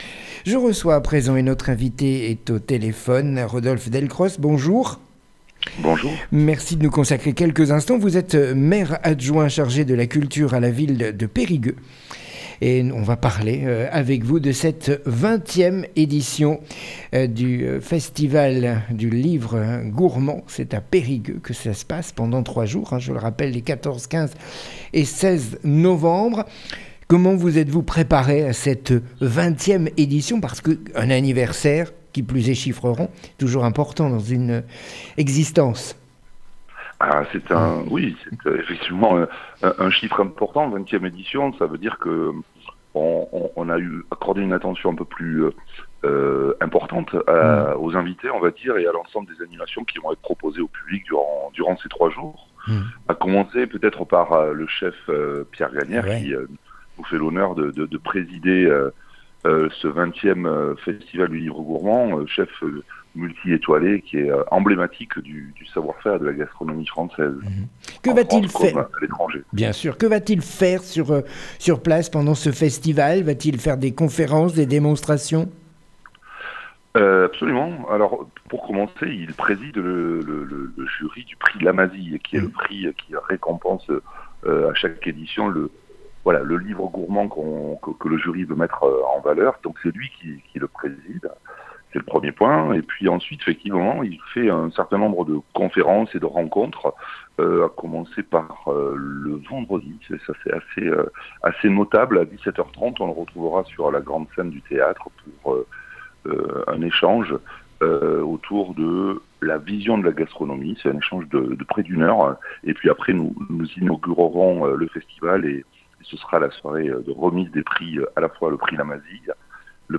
RODOLPHE DELCROS maire adjoint chargé de la culture à la ville de PERIGUEUX présente la 20éme édition du festival du livre gourmand